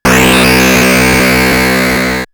Hum12.wav